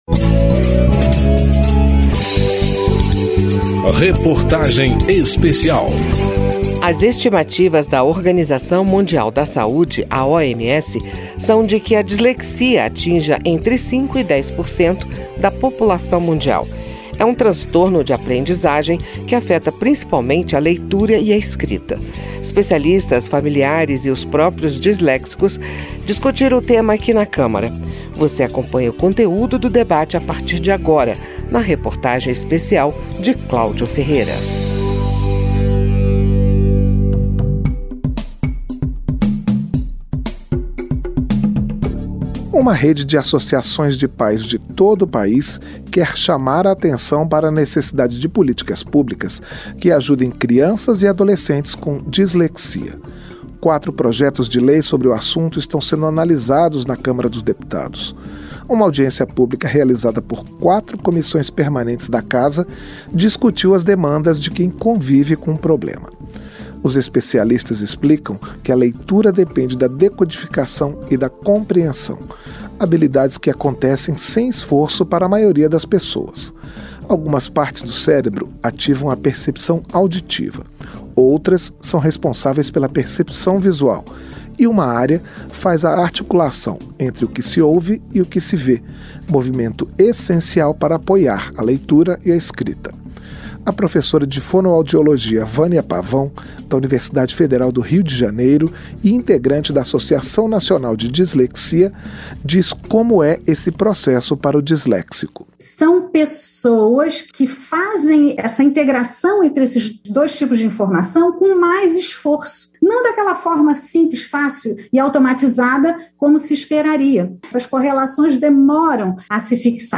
Reportagem Especial
É um transtorno de aprendizagem, que afeta principalmente a leitura e a escrita. Especialistas, familiares e os próprios disléxicos discutem o tema.